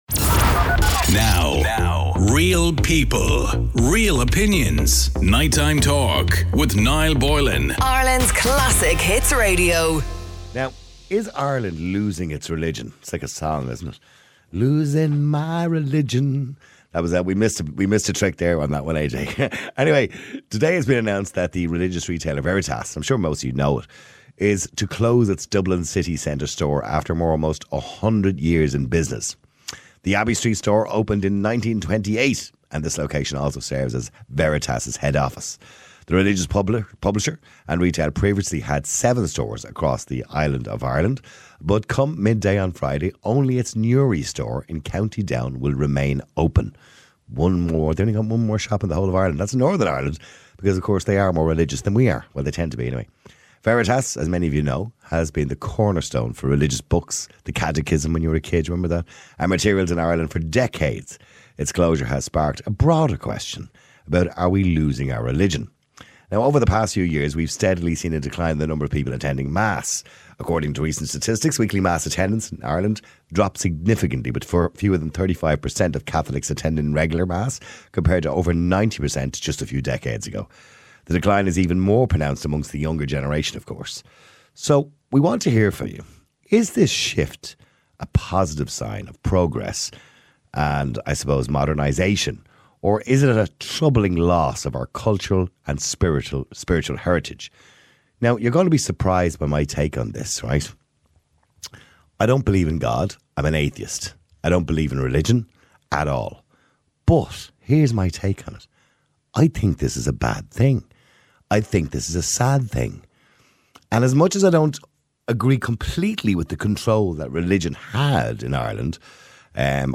A radio talk show that cares about YOU